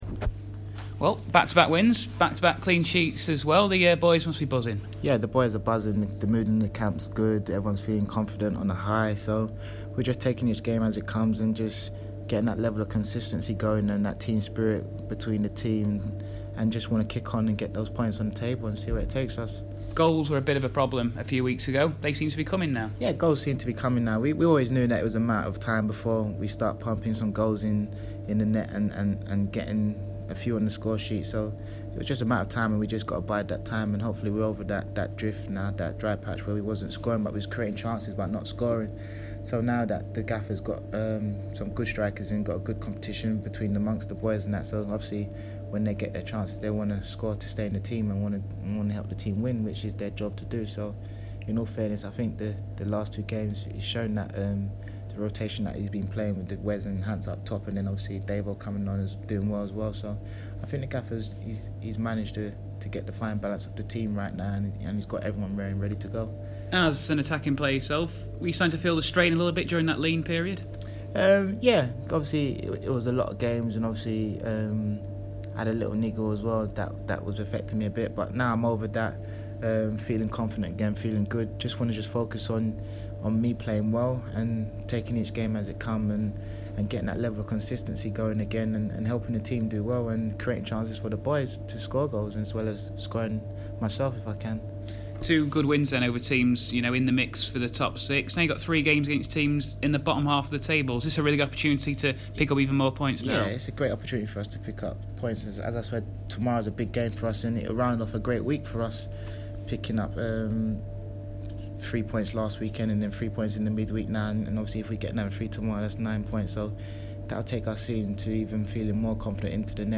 Bradford City Winger Kyel Reid speaks to Radio Yorkshire ahead of the game against Rochdale